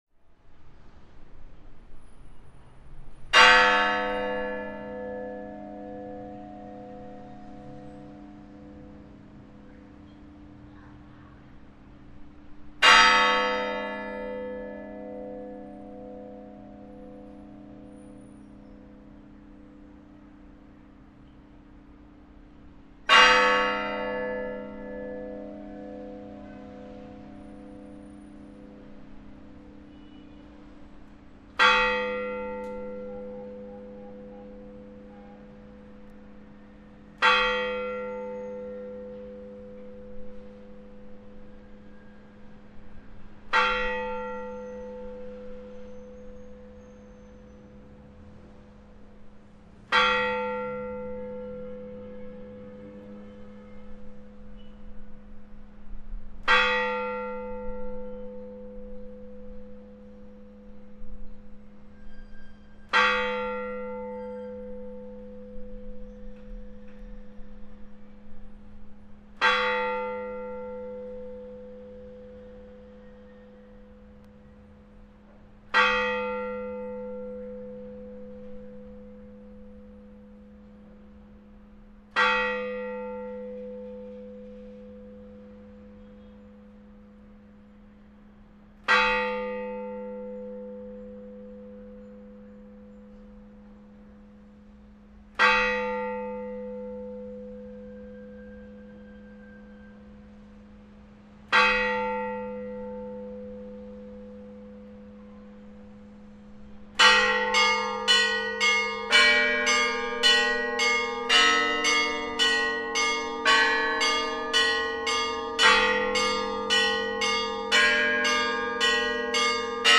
1. Благовест и будничный колокольный звон.mp3